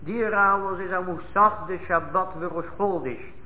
Pregao (announcement) by Chazzan on Rosh Chodesh to say Musaf for Shabbat Rosh Chodesh